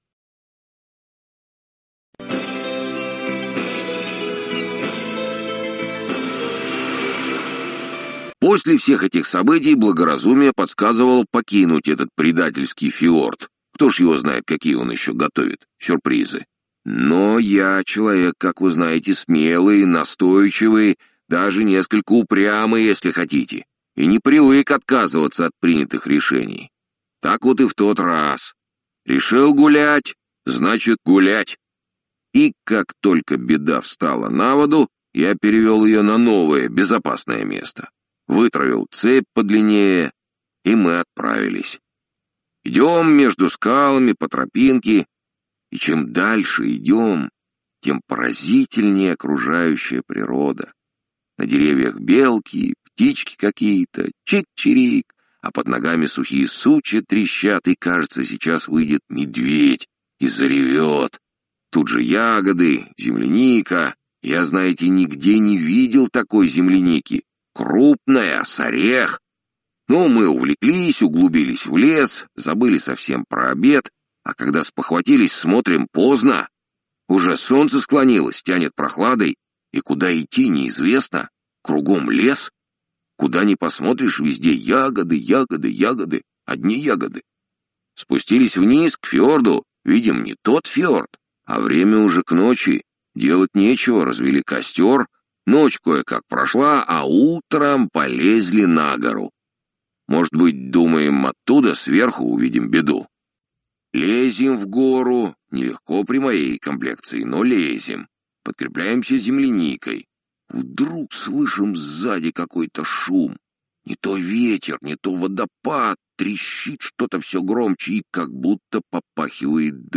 Lecture : Sergueï Tchonichvili (Сергей Чонишвили)